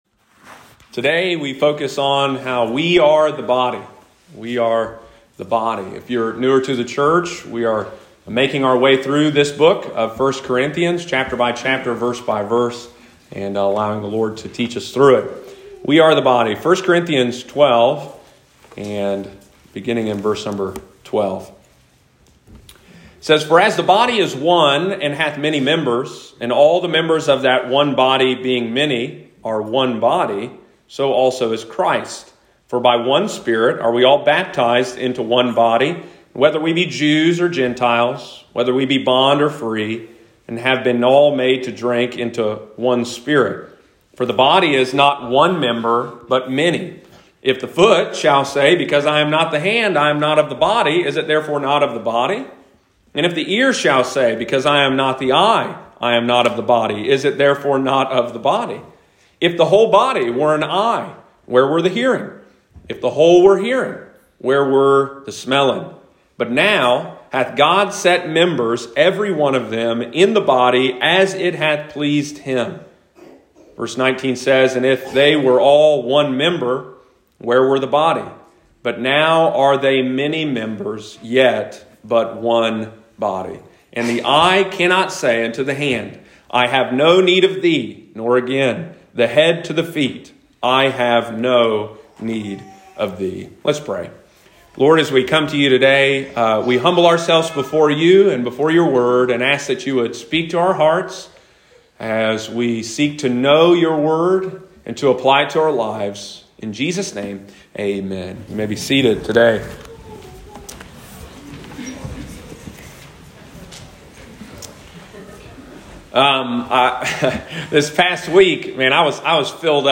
Sunday morning, September 26, 2021.